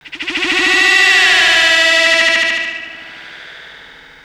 H370VOCAL.wav